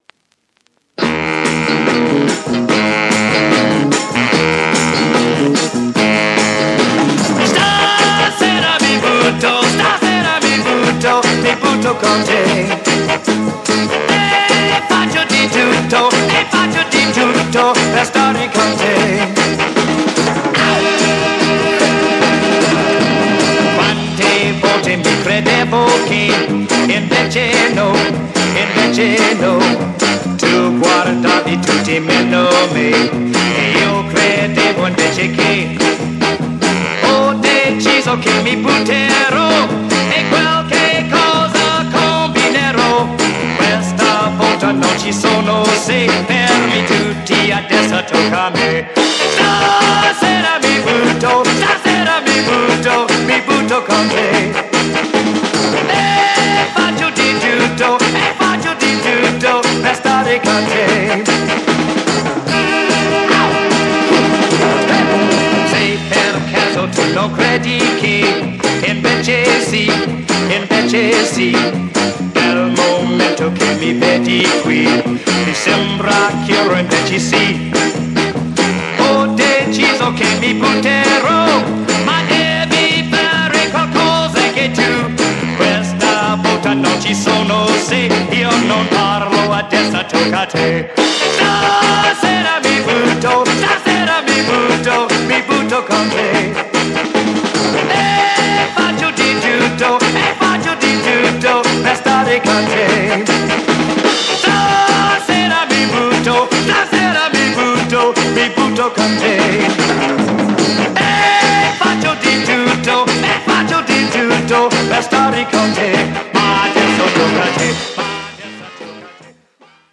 Italian Soul Mod single